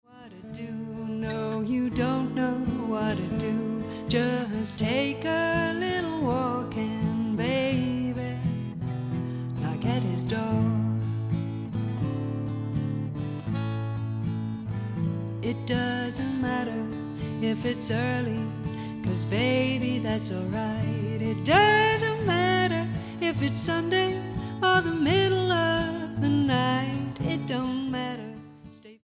STYLE: Roots/Acoustic
1960s blues folk vein